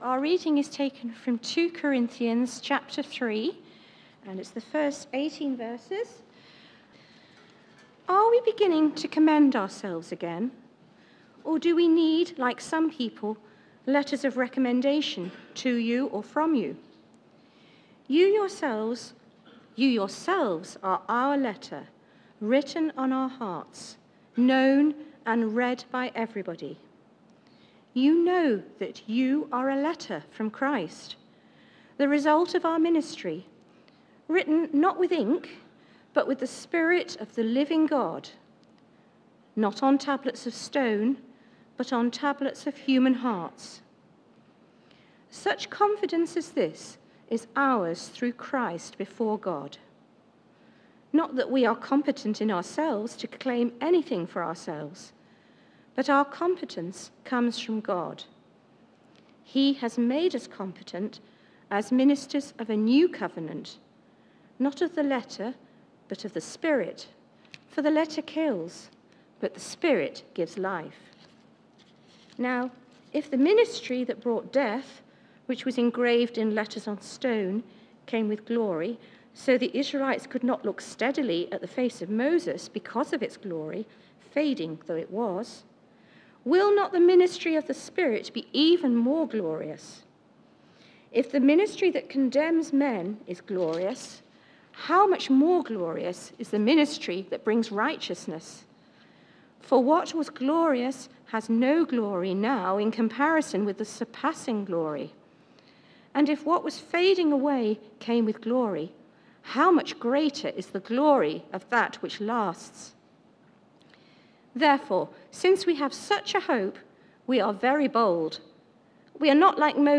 Sunday Service
Contradictions in Christian life Theme: Sufficiency thro insufficiency Sermon